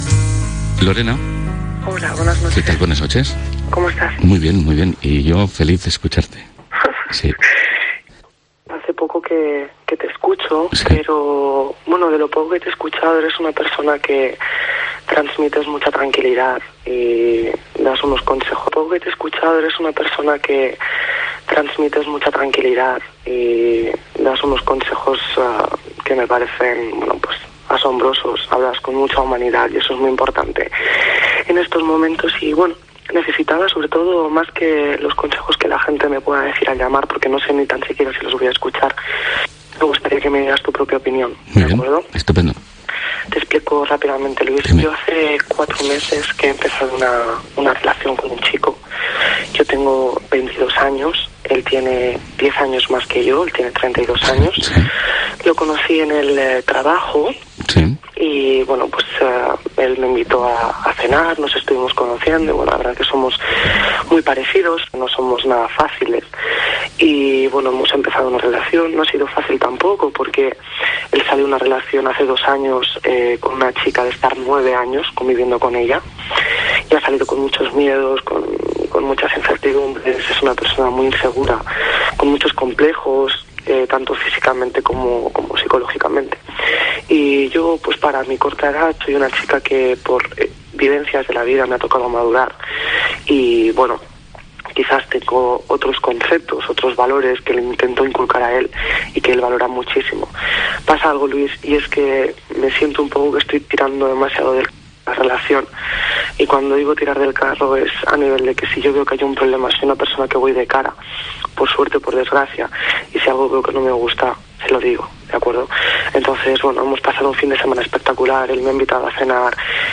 Todos los días de 1.30 a 6 de la madrugada a través de Cope Catalunya y Andorra.